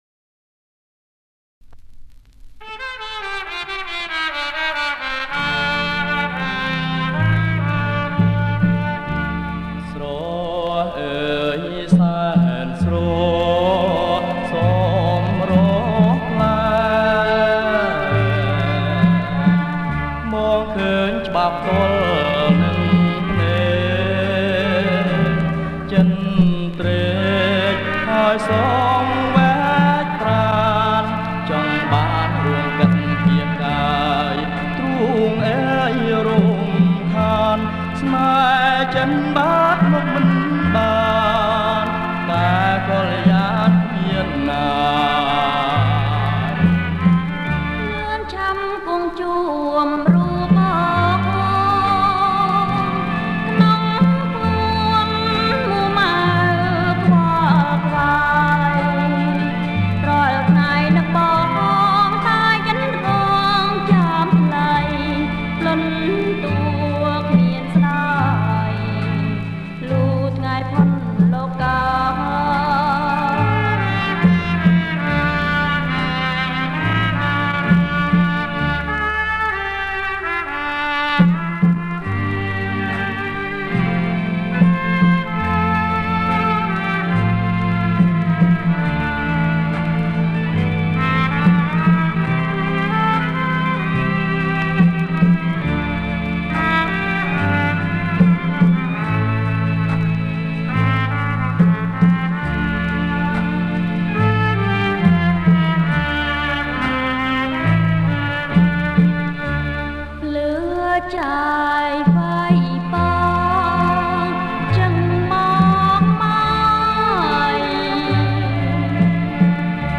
• ប្រគំជាចង្វាក់ Slow Folk